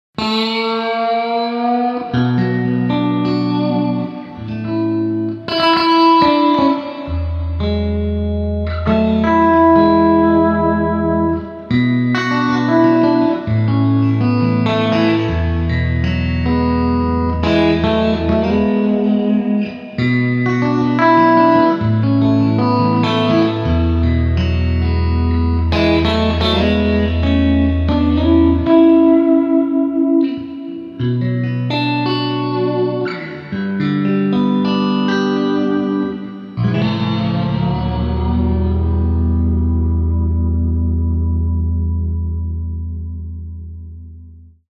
3. PLATE – the lush, warm reverb that got its name because a large metal plate was originally used to create it
Plate Reverb
Oceans-11Reverb-Plate-Reverb.mp3